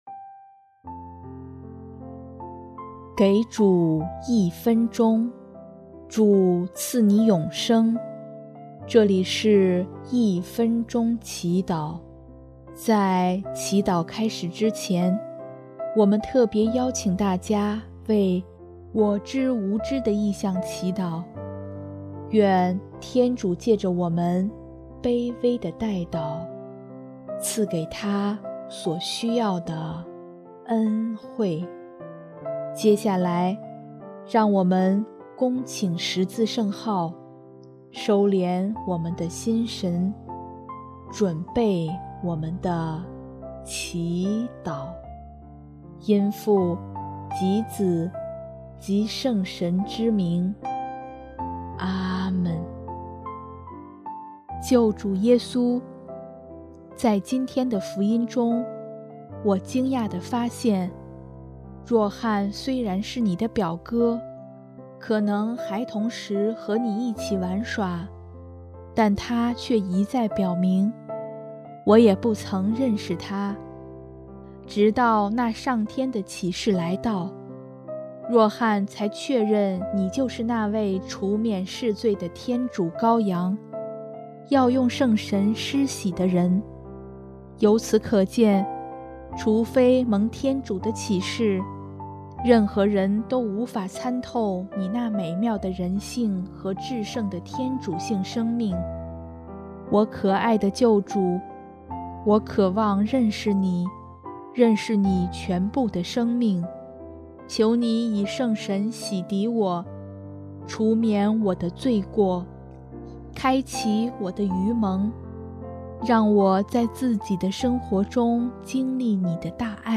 【一分钟祈祷】|1月18日 主，我渴望认识你